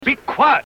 Tags: King Arthur Monty Python sounds Holy Grail audio clips Graham Chapman Funny Movie quotes